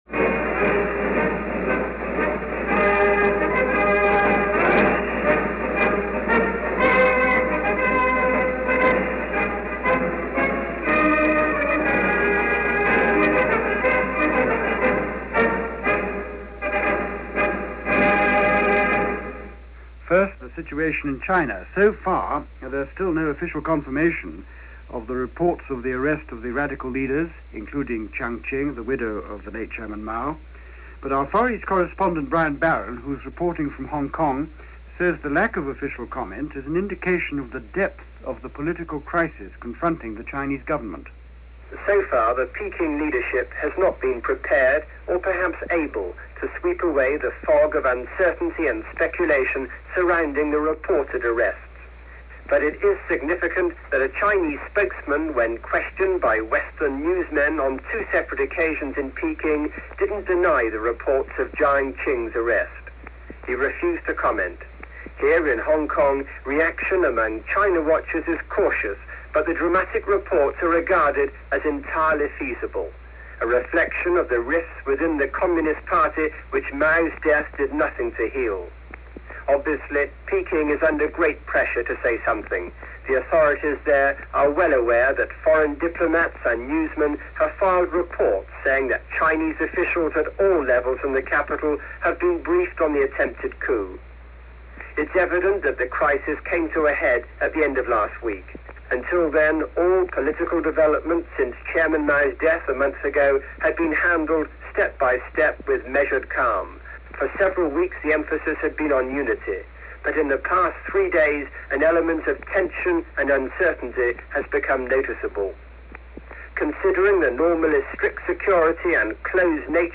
October 12, 1976 - After Mao - China And The Fog Of Uncertainty - news for this day in 1976 as presented by the BBC World Service.